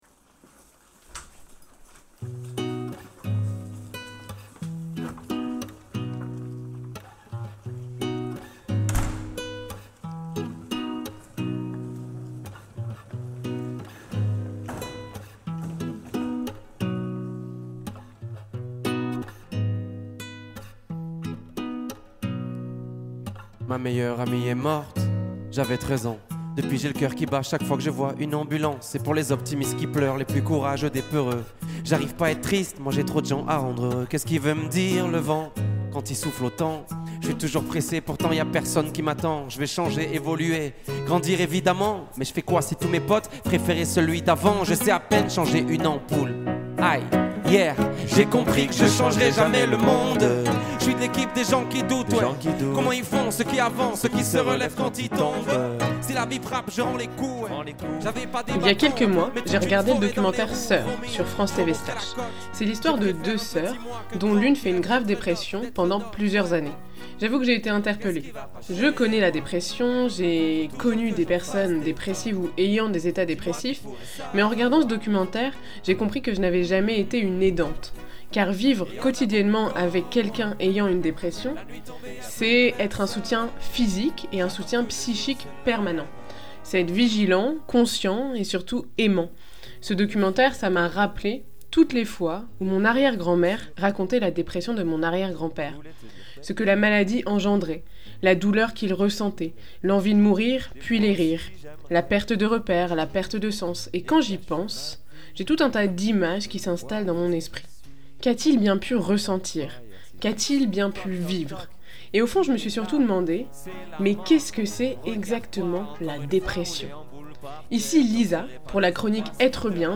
Pour cet épisode j'ai eu recours à de nombreuses interviews, denses, intenses, riches, passionnantes et malheureusement je n'ai pas pu toutes les exploiter dans leur entièreté.